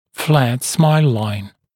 [flæt smaɪl laɪn][флэт смайл лайн]плоская линия улыбки